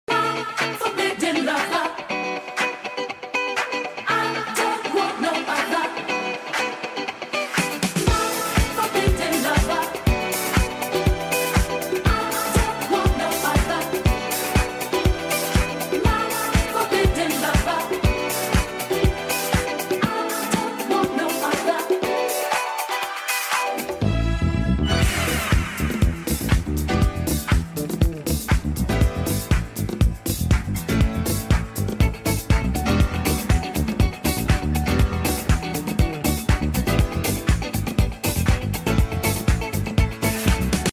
disco >>> sound effects free download